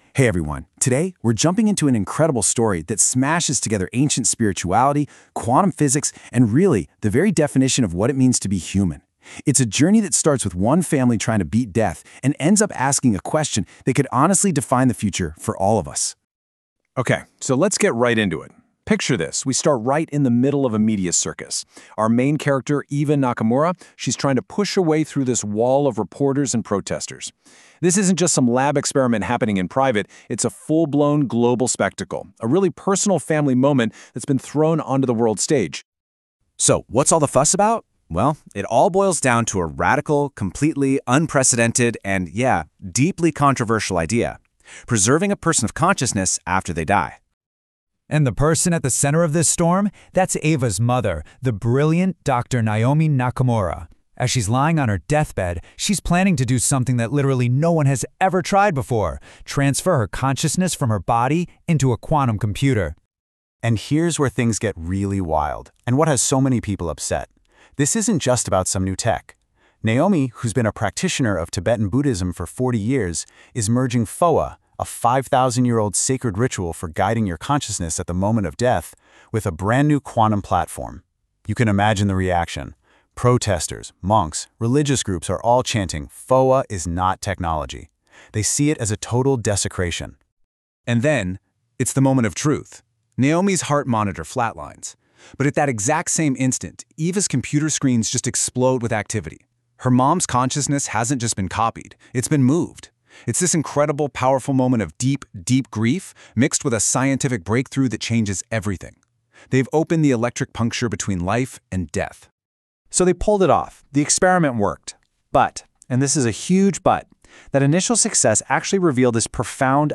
Review of Electric Puncture